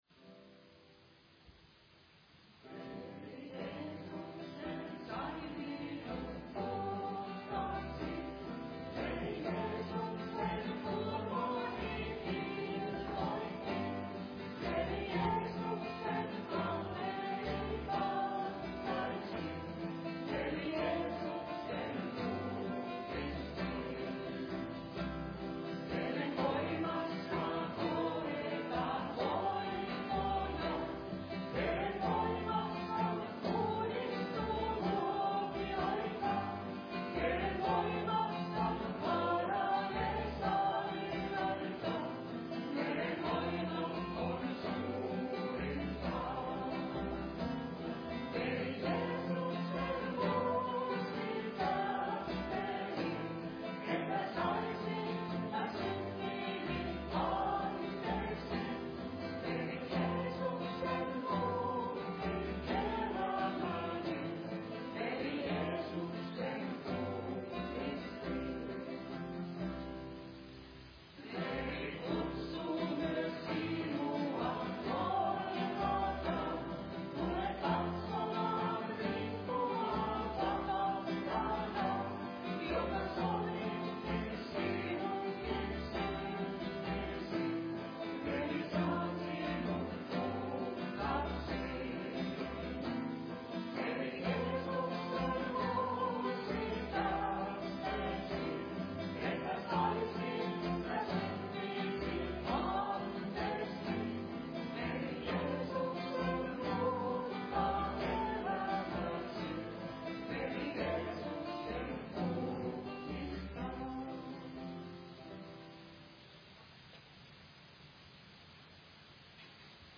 Siiloan-seurakunnan Raamattutunnit podcastina.